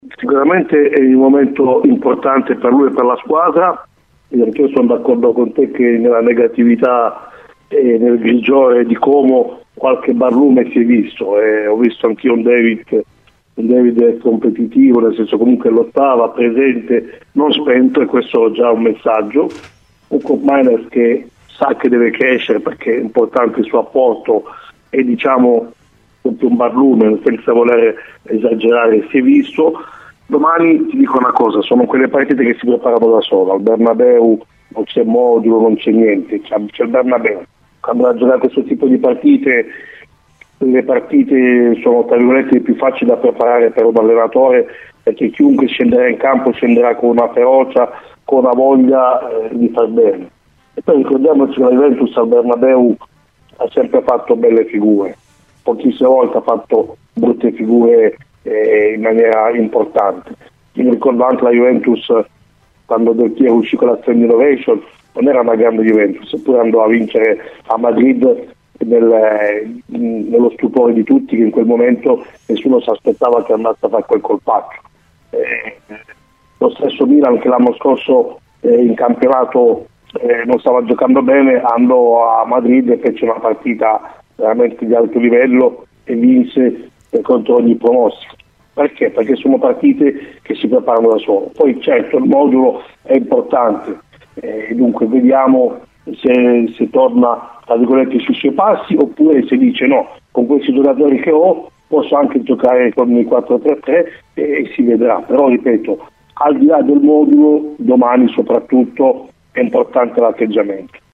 Ospite di “Cose di Calcio” su Radio Bianconera